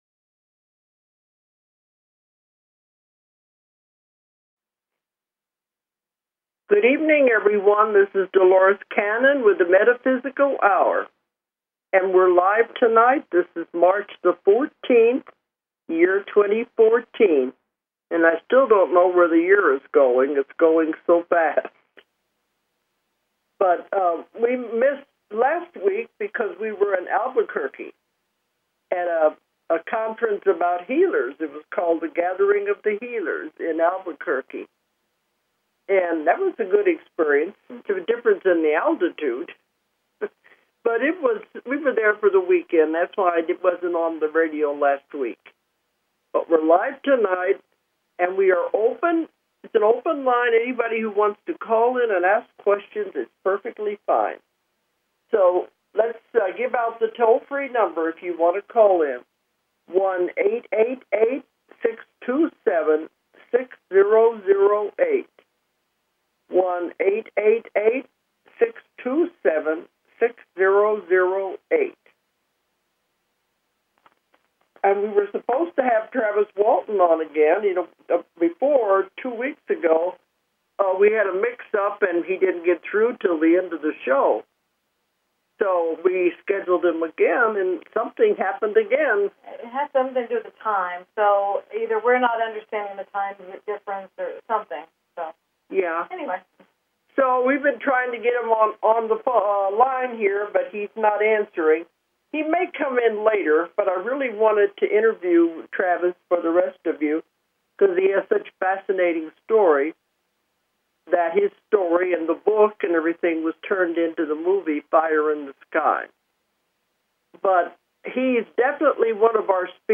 Talk Show Episode, Audio Podcast, The_Metaphysical_Hour and Courtesy of BBS Radio on , show guests , about , categorized as